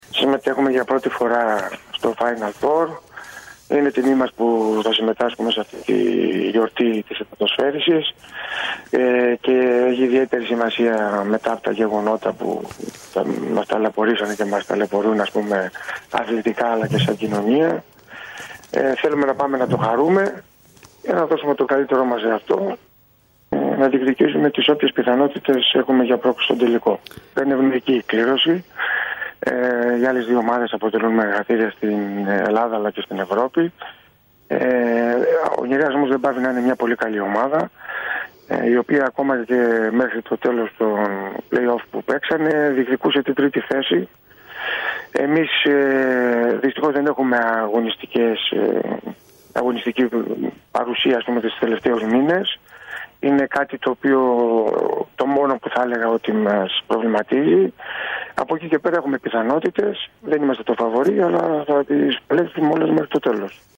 μίλησε στην πρωινή ενημερωτική εκπομπή της ΕΡΤ Πάτρας, για την παρθενική συμμετοχή της Πατρινής ομάδας στο Final-4  και τις πιθανότητες πρόκρισης στον τελικό.